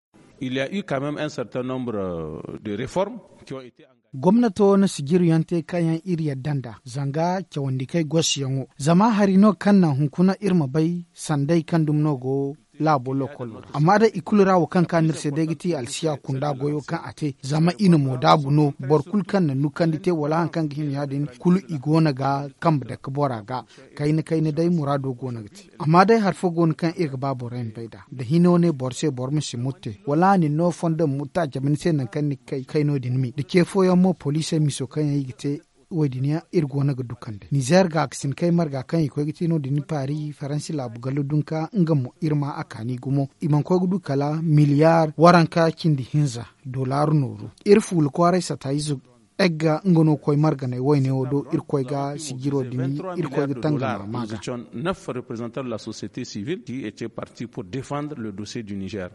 Magazine en zerma